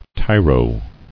[ti·ro]